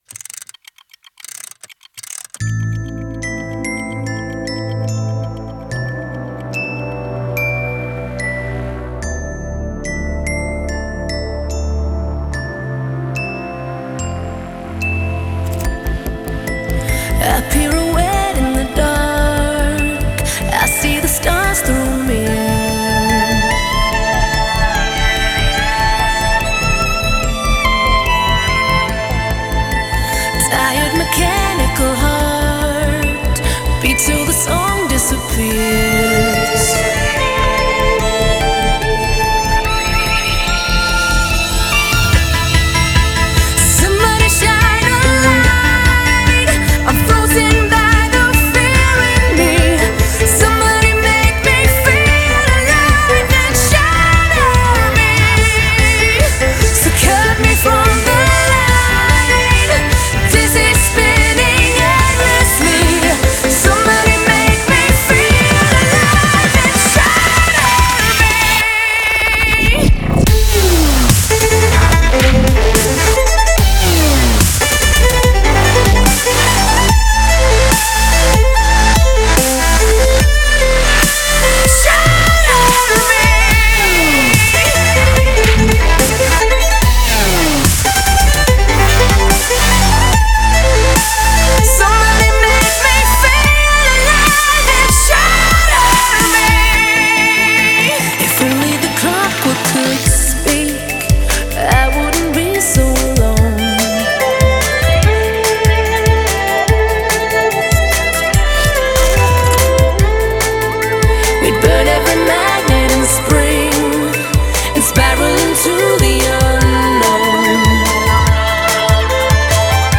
Категория: Электро музыка » Дабстеп